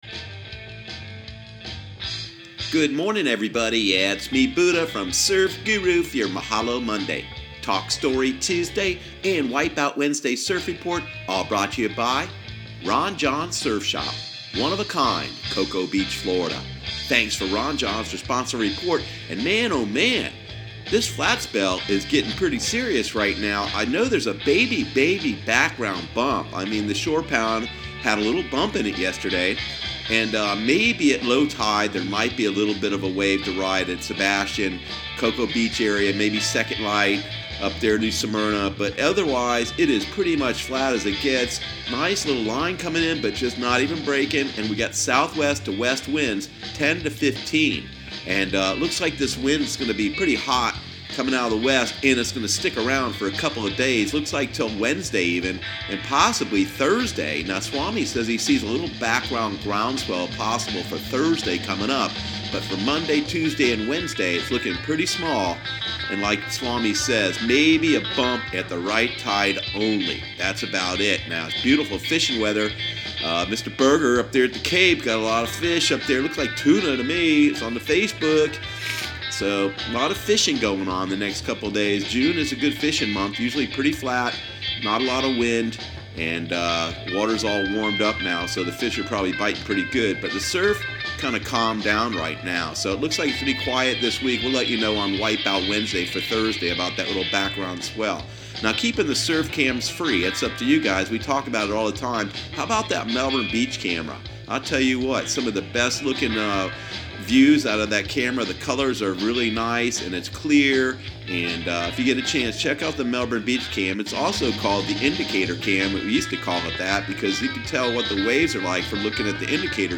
Surf Guru Surf Report and Forecast 06/04/2018 Audio surf report and surf forecast on June 04 for Central Florida and the Southeast.